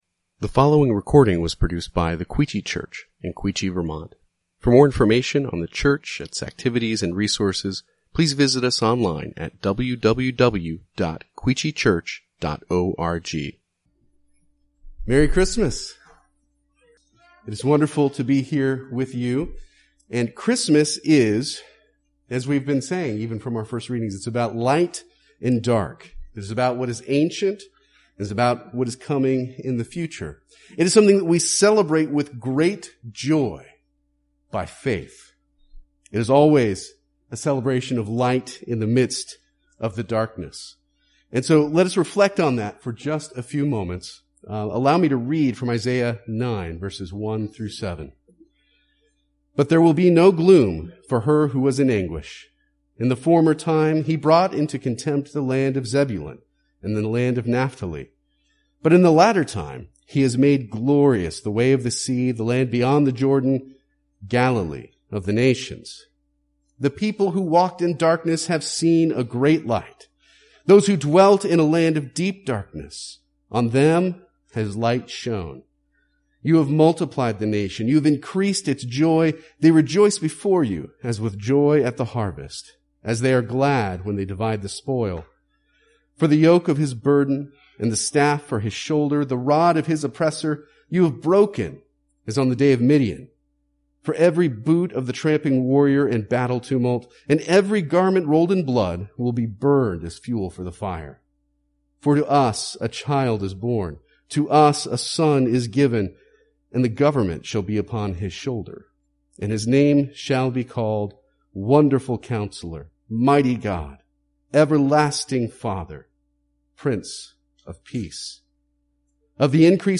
Quechee Church | Sermon Categories 2020